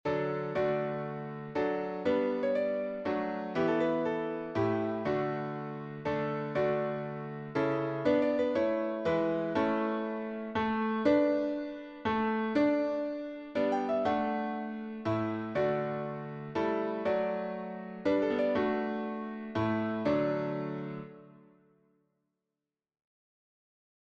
Meter: 8.6.8.6
Key: D Major